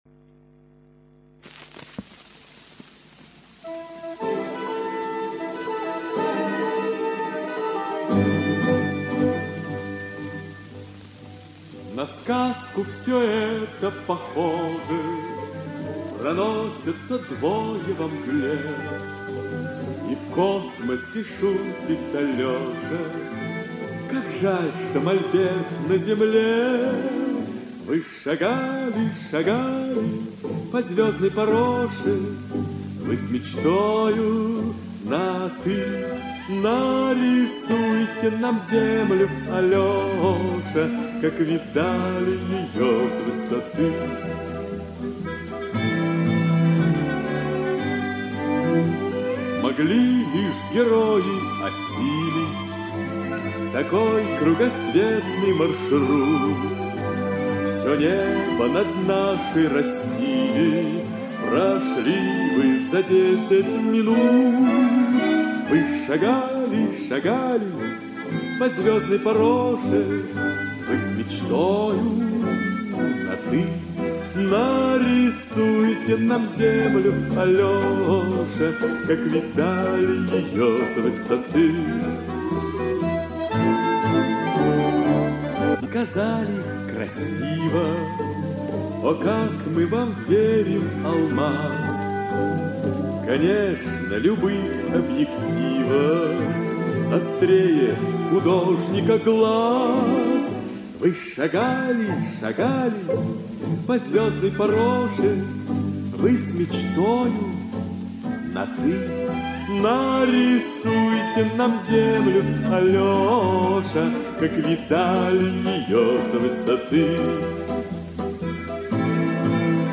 Инстр. ансамбль